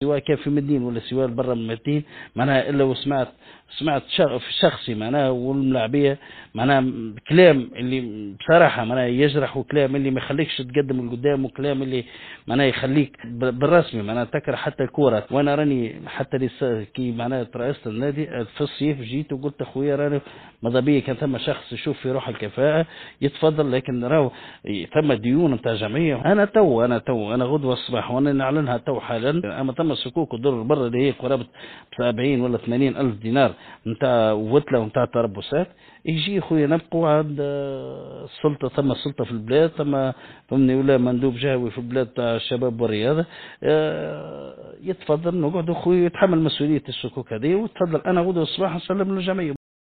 حوار خاص